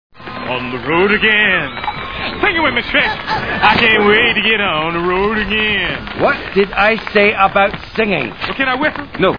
Shrek Sound Bites